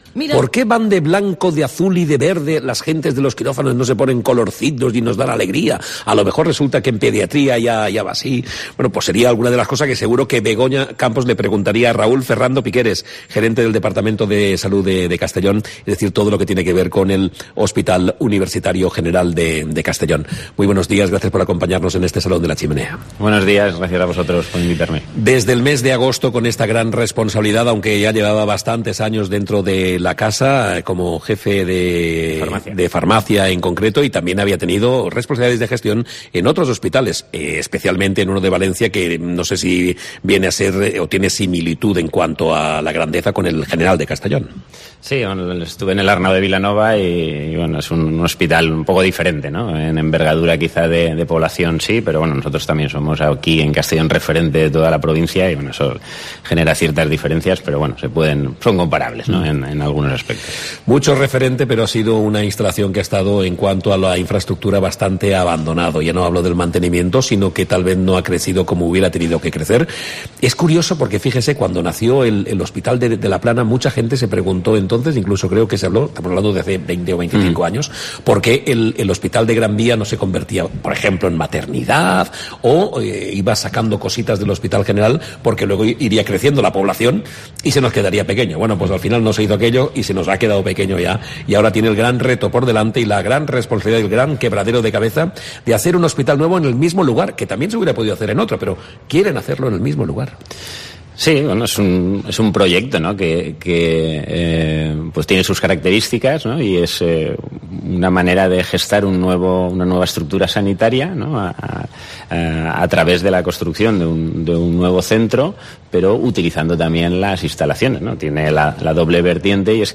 ha analizado en los micrófonos de COPE el proyecto de construcción del nuevo edificio del Hospital General. Un proyecto que apareció al final de la anterior legislatura, como una de las grandes bazas de Ximo Puig cara a las elecciones, pero que ahora ha sufrido una reformulación.